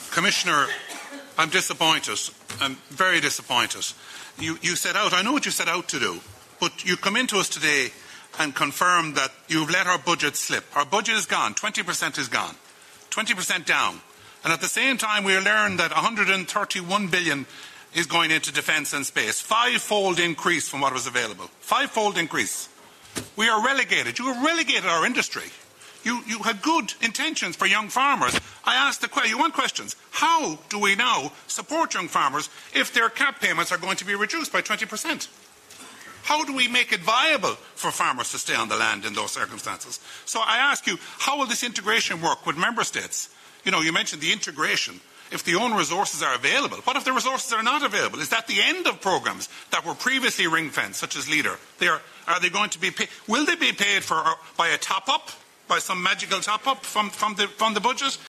Within the past hour, Independent Ireland MEP Ciarán Mullooly responded to Commissioner Hansen in the parliament, after he confirmed there will be a basic cut of over 20% in the cap payment.